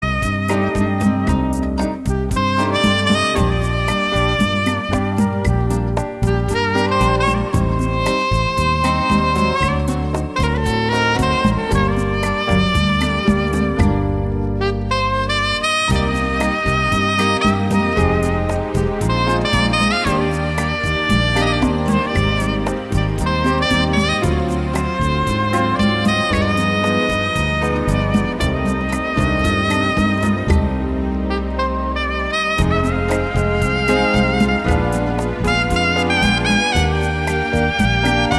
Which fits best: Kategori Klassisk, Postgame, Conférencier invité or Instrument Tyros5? Kategori Klassisk